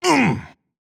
Grunt2.wav